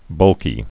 (bŭlkē)